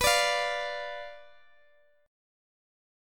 Bdim Chord (page 2)
Listen to Bdim strummed